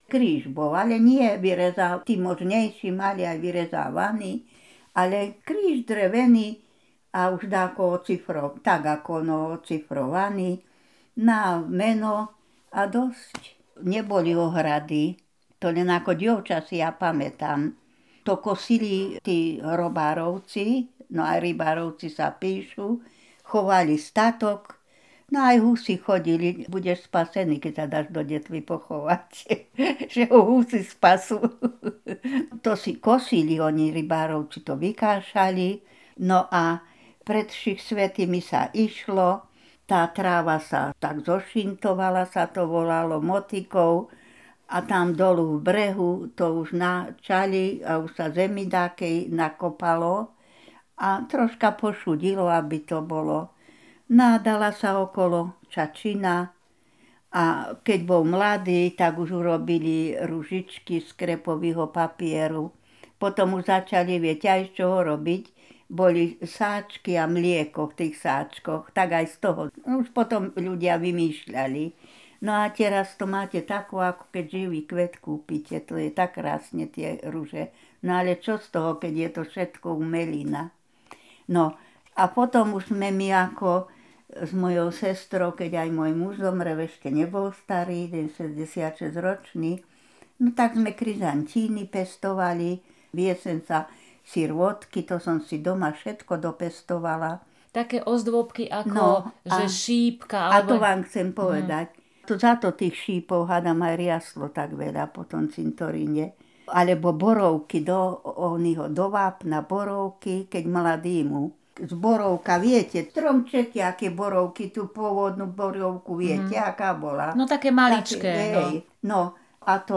Popis spomienkové rozprávanie o výzdobe hrobov pred sviatkom Všetkých svätých
Miesto záznamu Detva